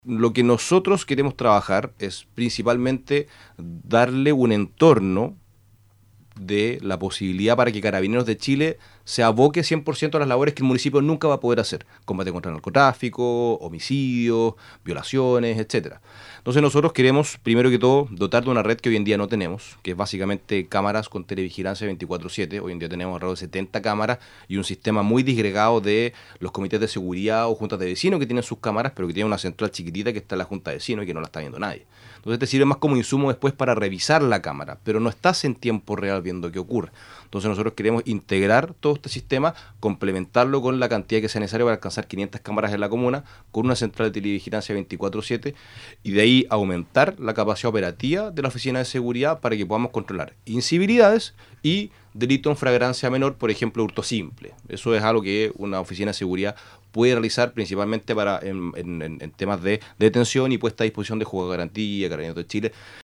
En entrevista con Nuestra Pauta, el alcalde comenzó señalando que apenas comenzó su gestión debió enfrentar una situación compleja a propósito del traspaso de los servicios de educación al nuevo Servicio Local de Educación Pública Andalién Costa.